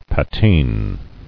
[pa·tine]